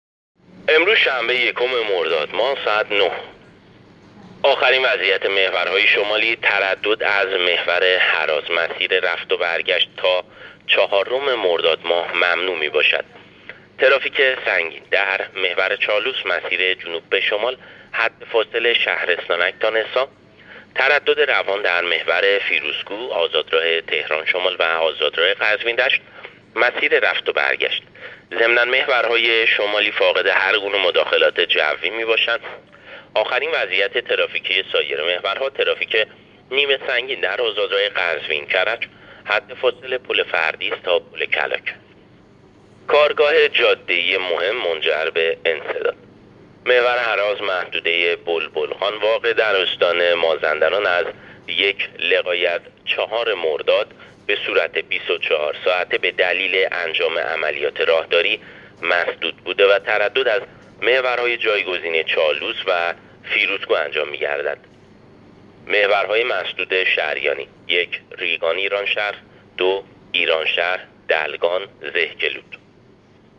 گزارش رادیو اینترنتی از آخرین وضعیت ترافیکی جاده‌ها تا ساعت ۹ یکم مرداد ماه؛